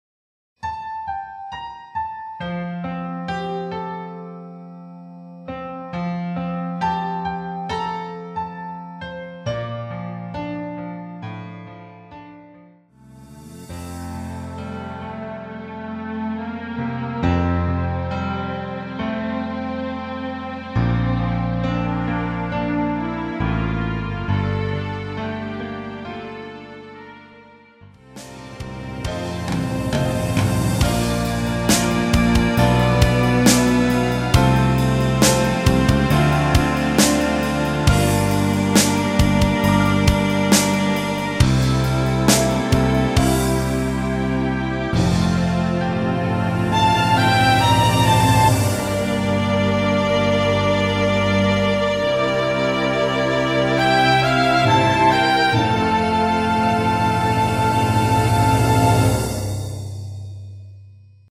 엔딩이 너무 길어 라이브 하시기 좋게 엔딩을 짧게 편곡 하였습니다.(미리듣기 참조)
앞부분30초, 뒷부분30초씩 편집해서 올려 드리고 있습니다.
중간에 음이 끈어지고 다시 나오는 이유는